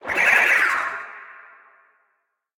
File:Sfx creature nootfish death 01.ogg - Subnautica Wiki
Sfx_creature_nootfish_death_01.ogg